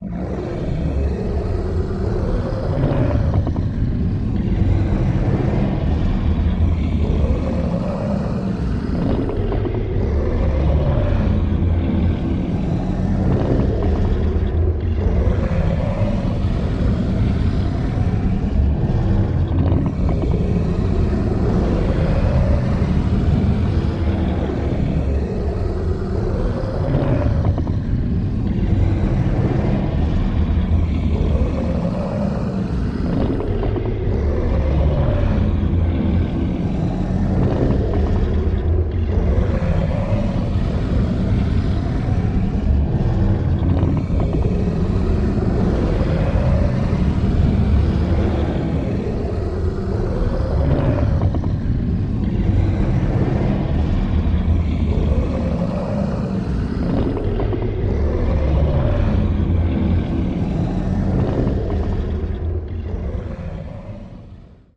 Creature Lair with Creatures